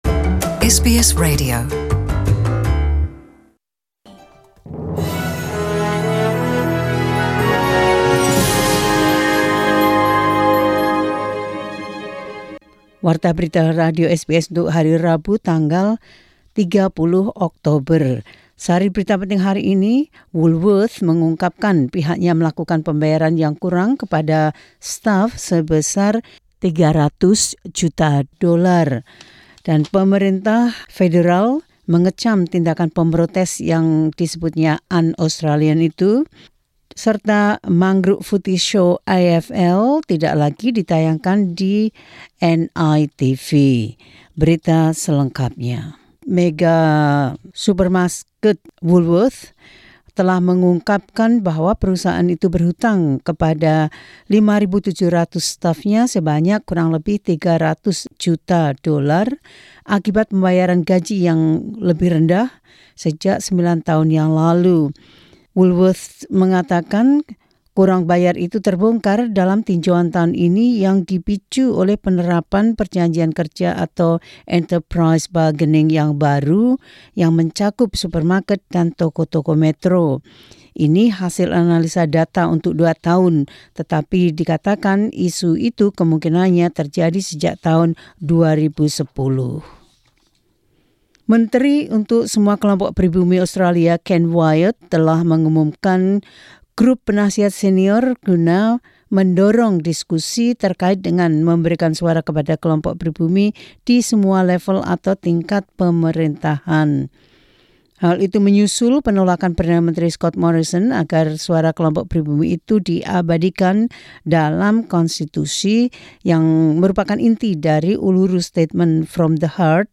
SBS Radio News in Indonesian 30 Oct 2019.